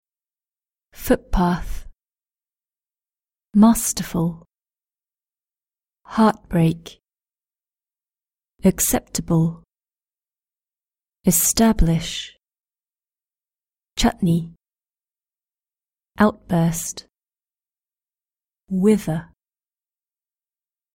Glottal Stop: footpath, heartbreak, chutney, outburst
british-english-british-accent-glottal-stop-4.mp3